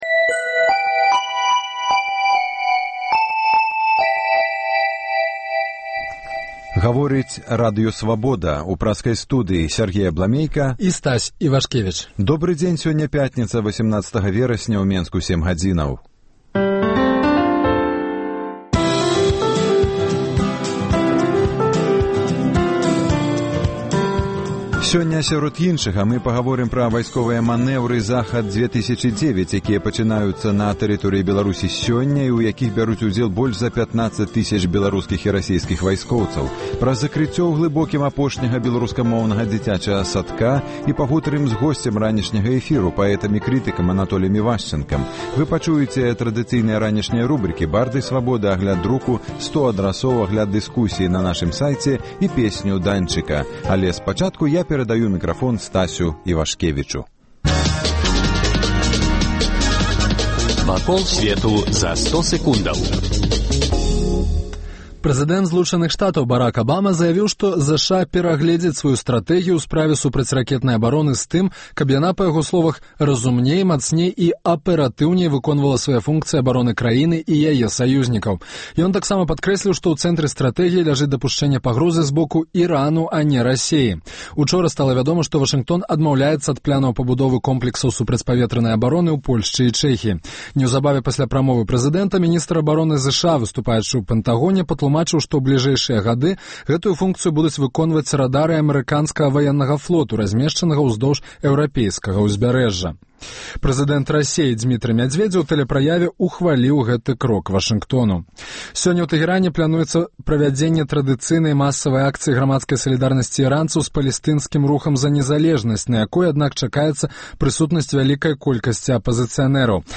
Ранішні жывы эфір
Навіны Беларусі й сьвету, надвор'е, агляд друку, гутарка з госьцем, ранішнія рэпартажы, бліц-аналіз, музычная старонка